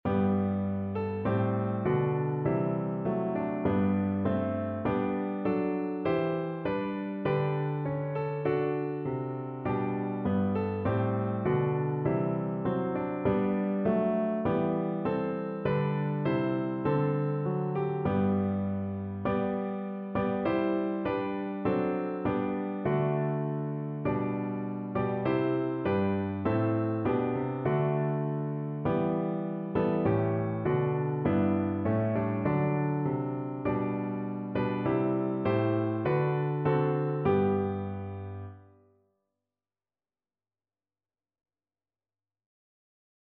Piano version
4/4 (View more 4/4 Music)
Piano  (View more Easy Piano Music)
Classical (View more Classical Piano Music)